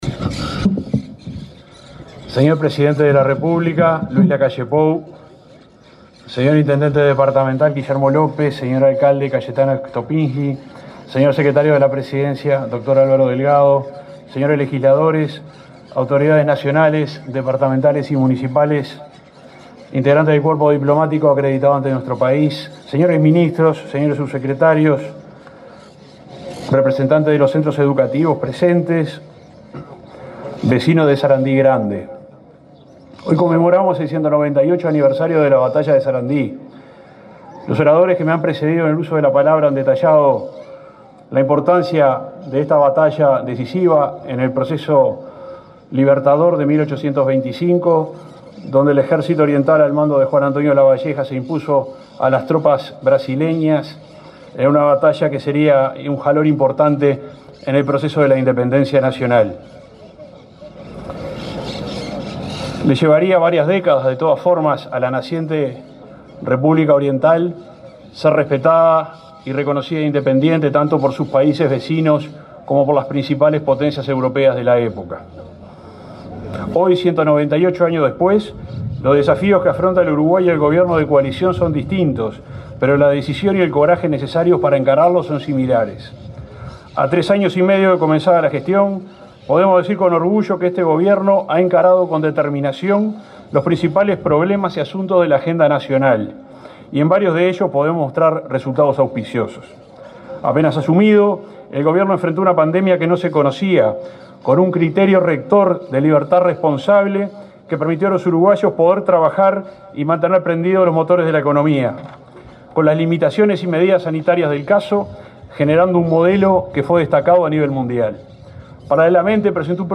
El subsecretario de Transporte, Juan José Olaizola, fue el orador en representación del Poder Ejecutivo, en Florida, en el acto conmemorativo del 198.